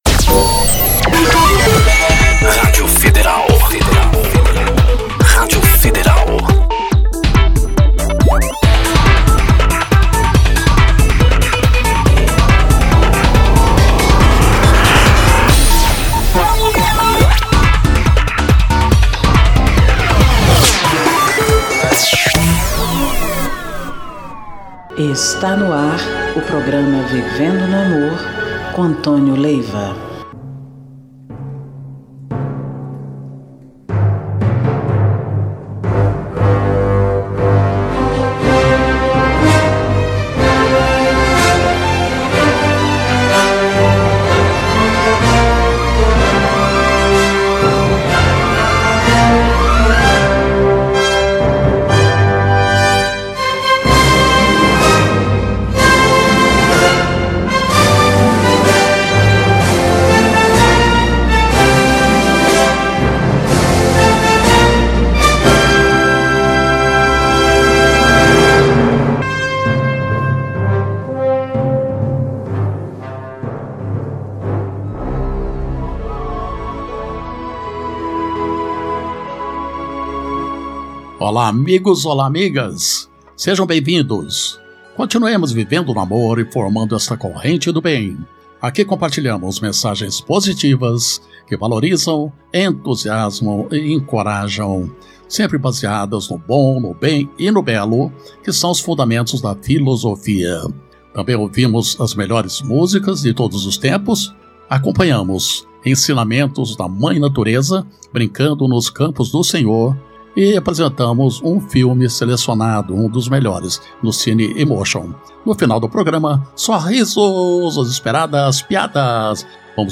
MÚSICAS E MENSAGENS